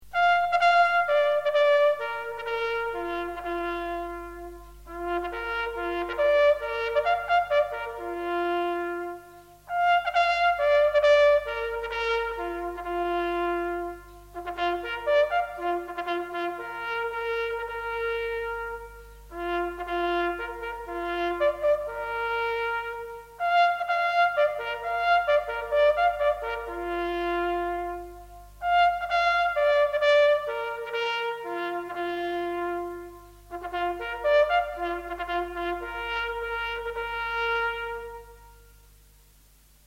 Retreat